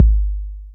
808-Kicks25.wav